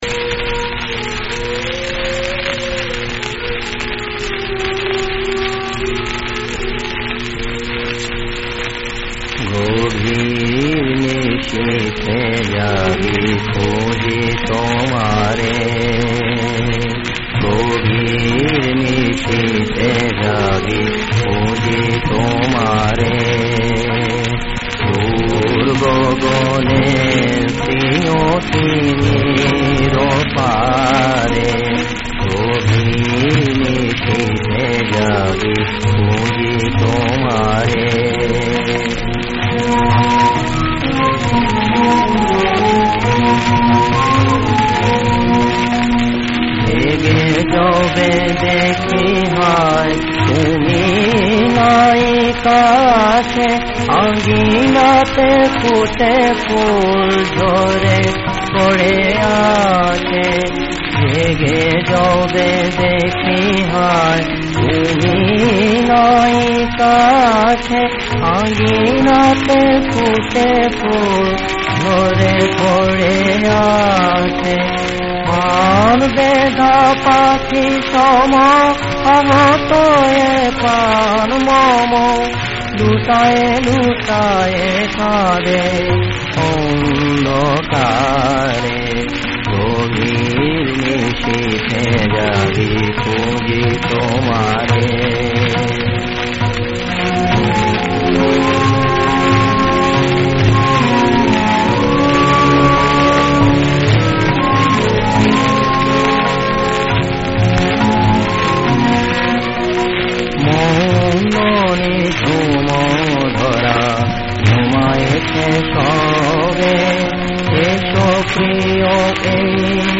রাগ দরবারী কানাড়া। তাল: ত্রিতাল।]
• সুরাঙ্গ: খেয়ালাঙ্গ